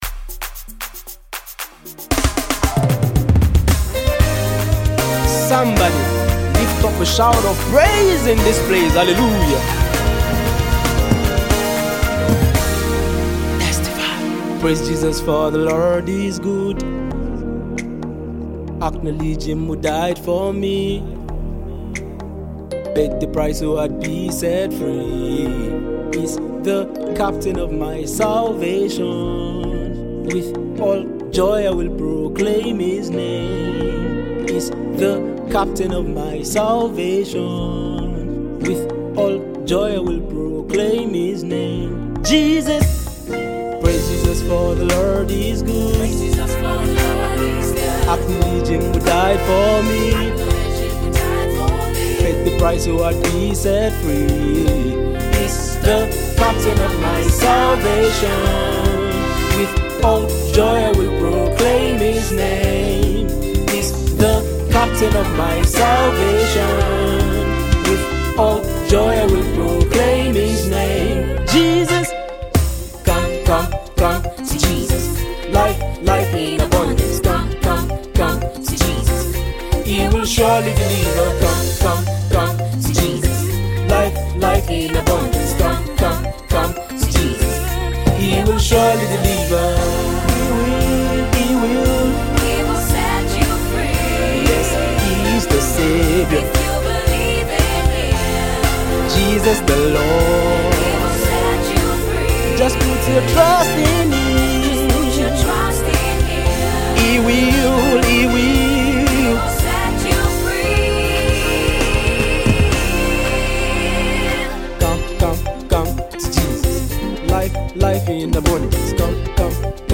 gospel track